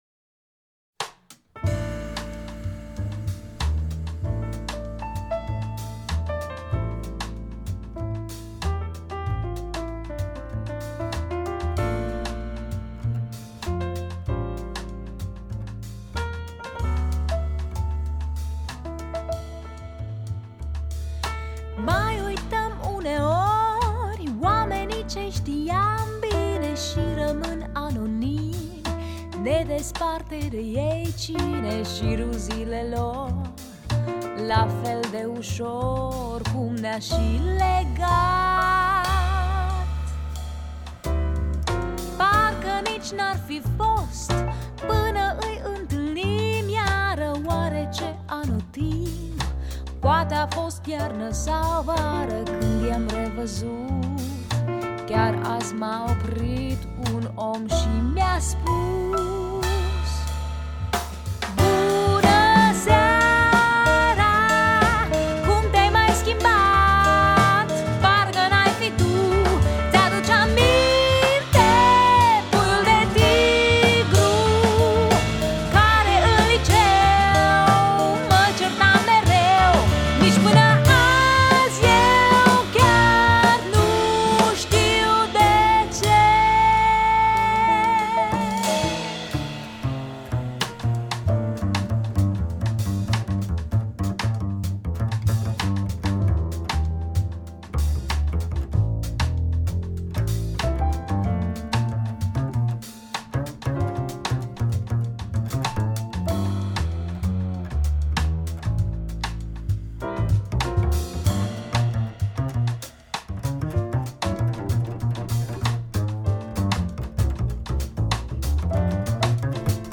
Äänitetty Kallio-Kuninkalassa, Järvenpäässä.